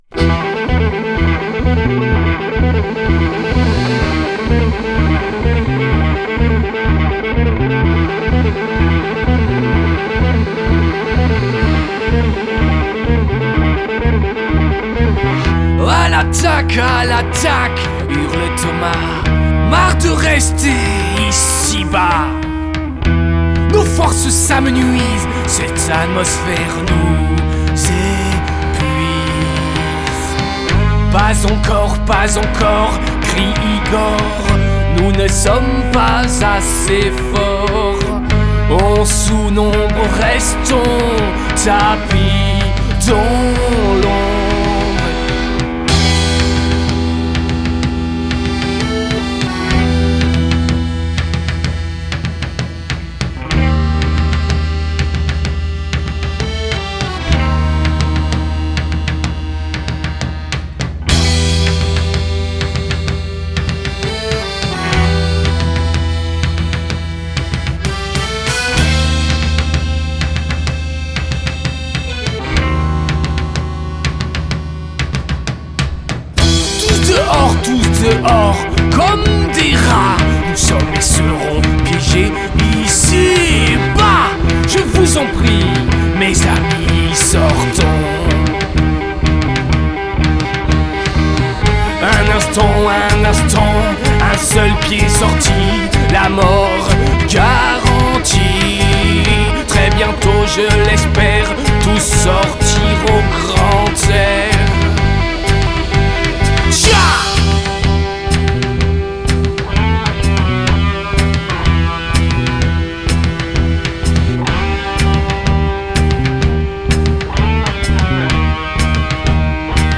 rock progressif